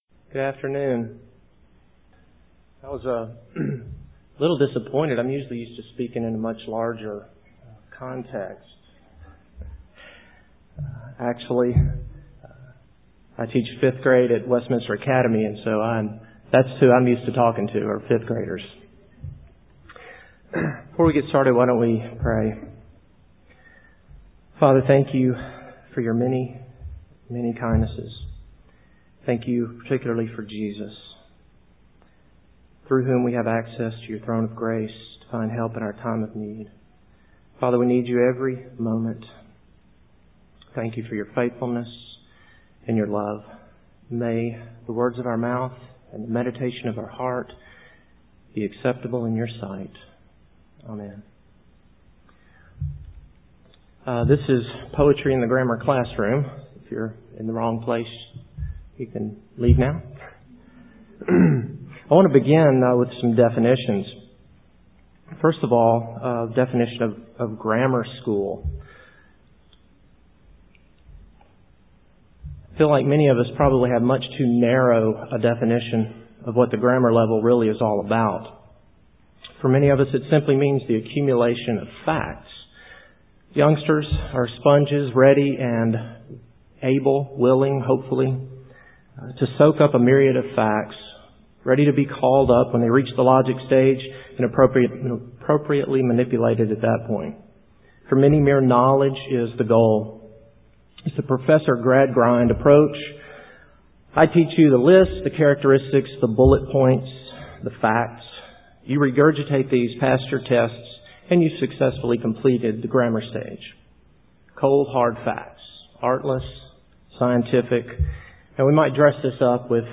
2005 Workshop Talk | 0:46:45 | K-6, Rhetoric & Composition